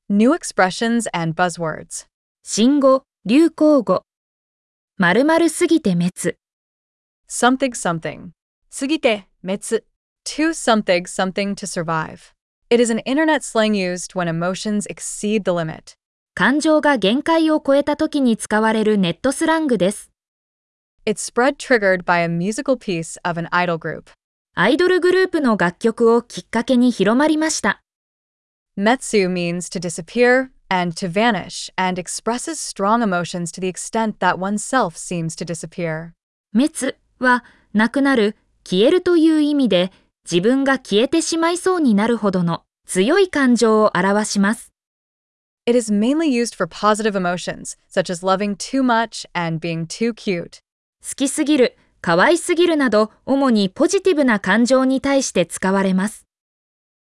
🗣 pronounced: xx sugite metsu